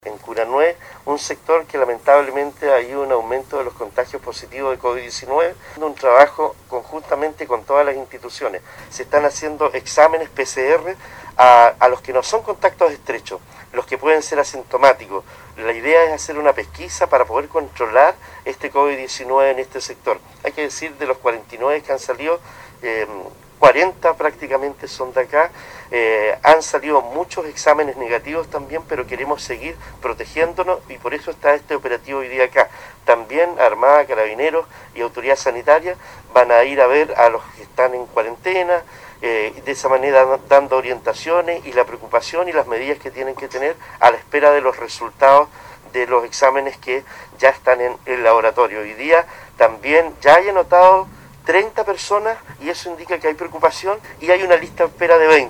El alcalde Cristian Ojeda expresó la preocupación por la situación que está  viviendo la comuna, y esta comunidad en especial, que se ha visto muy afectada.